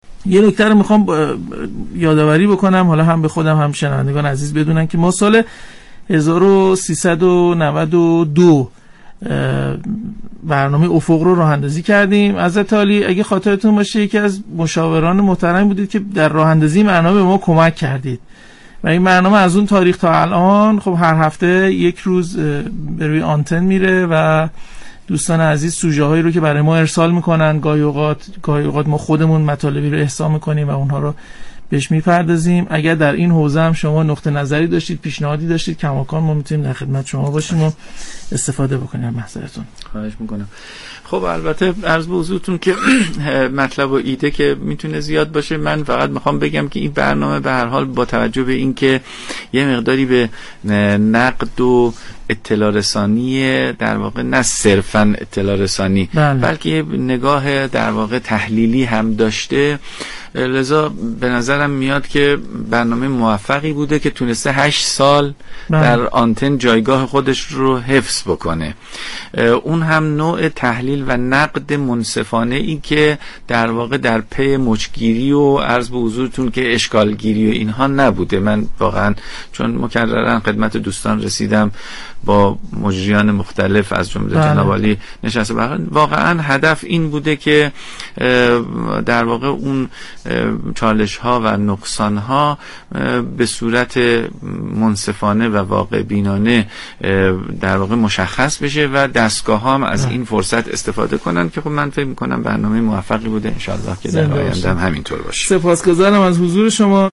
به گزارش پایگاه اطلاع رسانی رادیو قرآن ؛ سید علی سرابی قائم‌ مقام شورای عالی قرآن در گفتگو با برنامه افق ، بعنوان یكی از بانیان و مشاورین این برنامه ، افق را برنامه ای موفق دانست و گفت : برنامه افق با محوریت نقد منصافه و نگاه تحلیلی و پیگیری مطالبات رهبری از جامعه قرآنی برنامه موفقی است كه هشت سال روی آنتن رادیو قرآن بوده است .